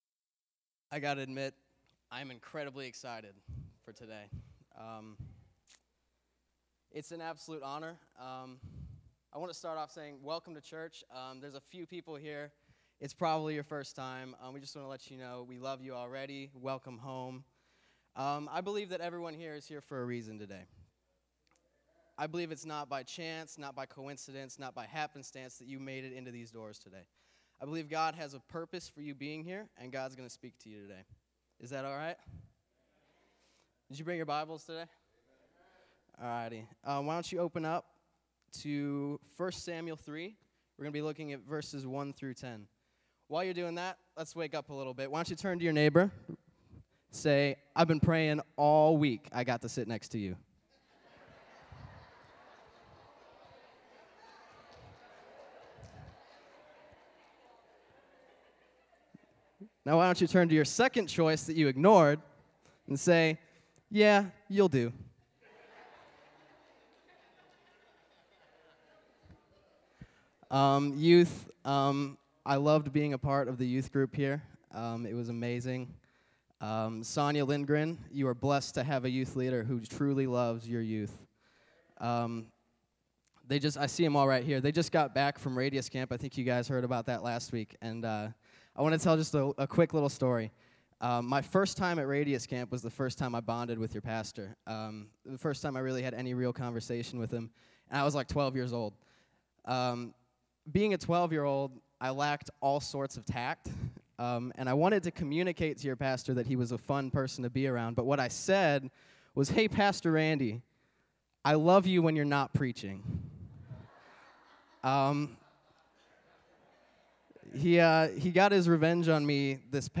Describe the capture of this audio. sermons preached at Grace Baptist Church in Portage, IN